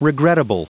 Prononciation du mot regrettable en anglais (fichier audio)
Prononciation du mot : regrettable